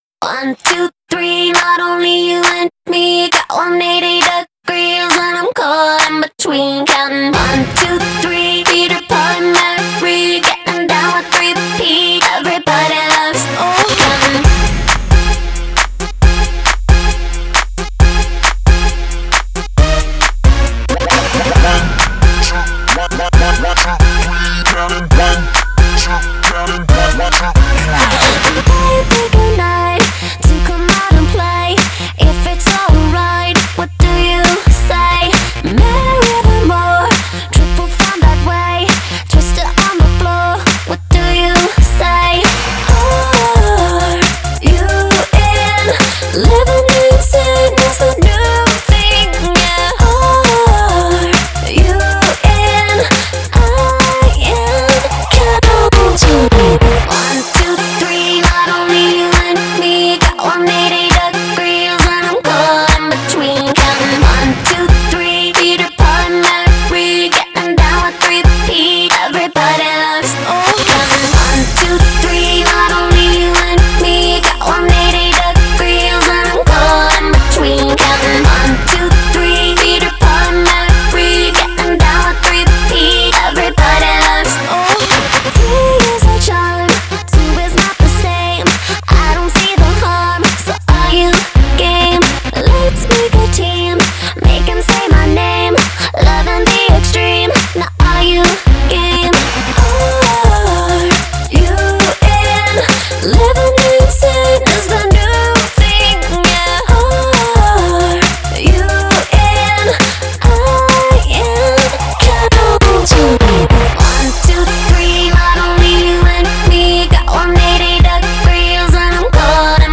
Танцевальная [10]